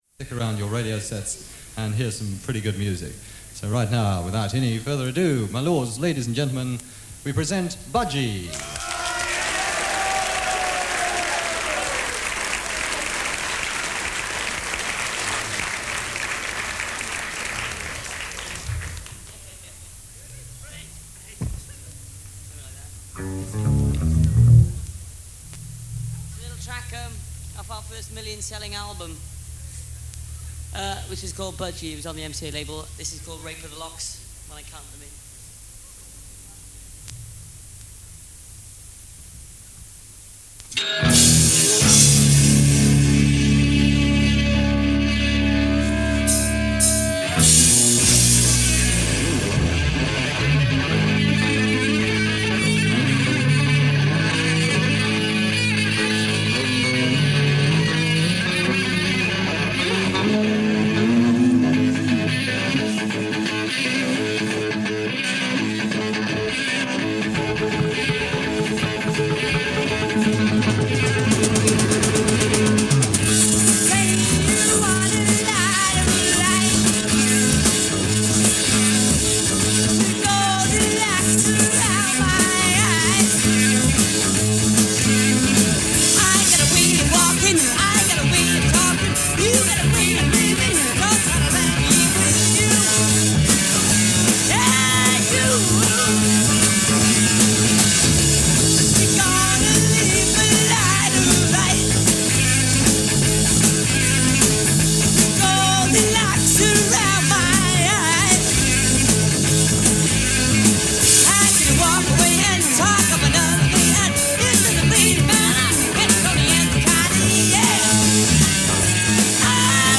They were loud and they played riffs
Honest head-banging – no ifs, ands or buts.